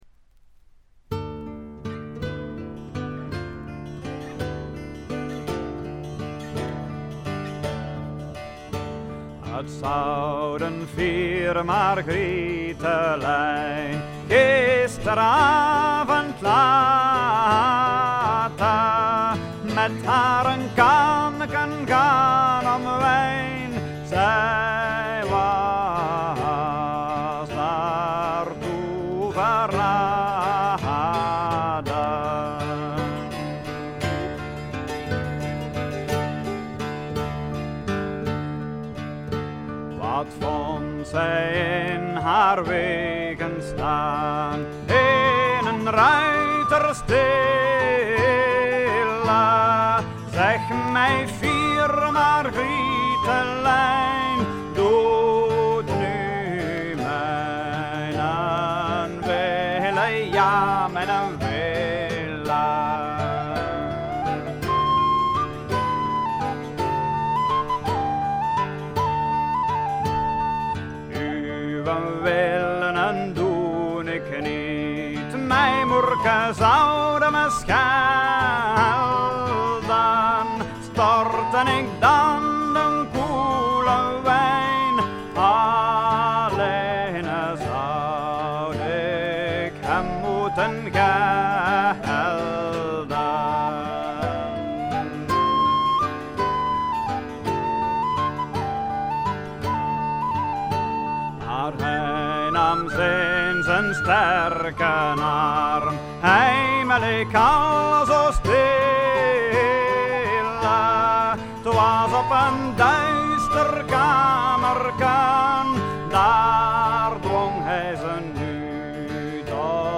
ほとんどノイズ感無し。
試聴曲は現品からの取り込み音源です。
Lute
Violin
Flute